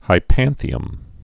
(hī-pănthē-əm)